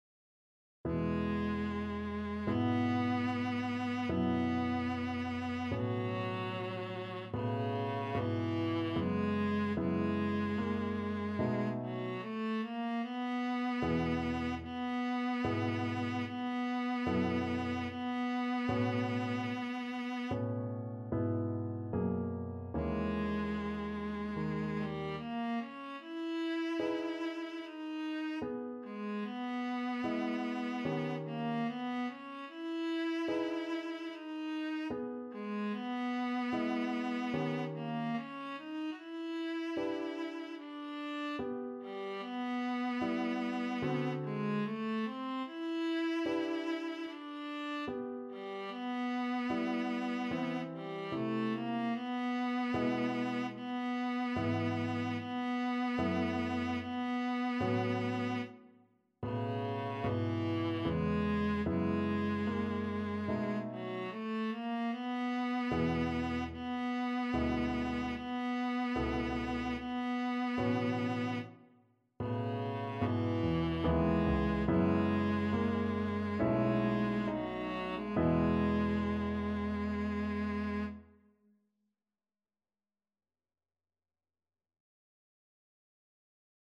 Viola version
Db4-F#5
4/4 (View more 4/4 Music)
Classical (View more Classical Viola Music)